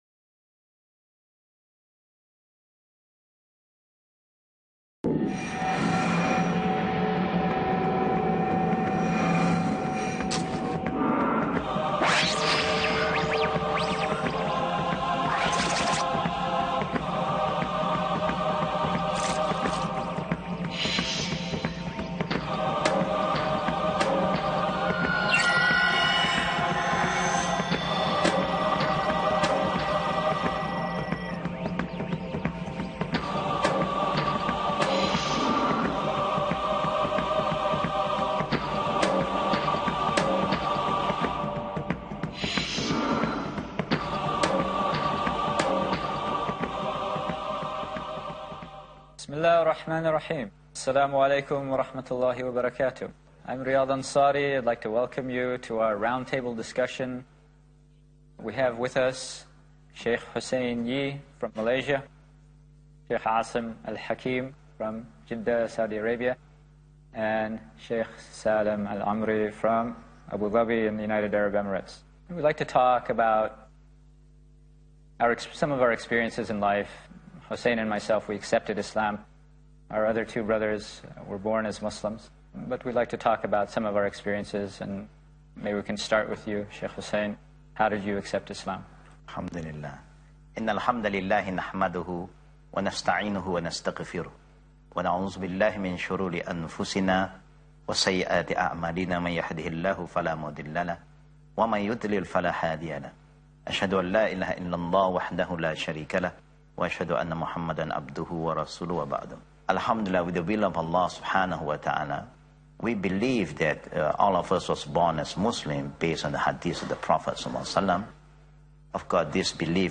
Four remarkable men from vastly different backgrounds — a Chinese Buddhist-turned-Christian from Malaysia, speakers from Saudi Arabia and Abu Dhabi, and a half-Afghan half-American — sit together to share their unique journeys to Islam. From Buddhist temples to Christian missionary schools, from atheism to finally reading the Quran, each story demonstrates that Allah guides whomever He wills, regardless of culture, ethnicity, or upbringing.